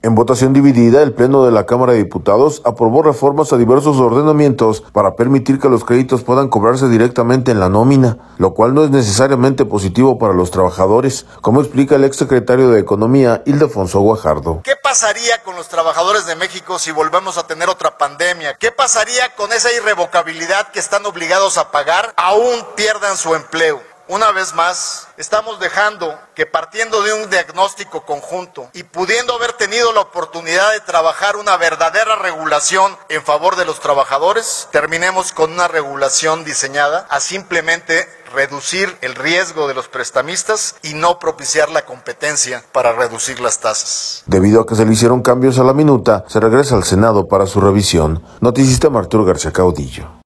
En votación dividida, el Pleno de la Cámara de Diputados aprobó reformas a diversos ordenamientos para permitir que los créditos puedan cobrarse directamente en la nómina, lo cual no es necesariamente positivo para los trabajadores, como explica el exsecretario de Economía, Ildefonso Guajardo.